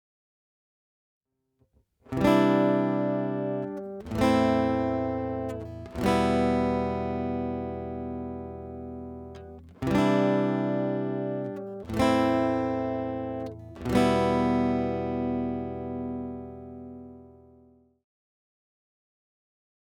Demo Audio realizzate presso Trees Music Studio di Cava de’Tirreni
Chitarra Acustica Arpeggio Dpa – Focusrite Red 8 Pre
Chitarra Acustica – Larrivee
Microfono – DPA 4090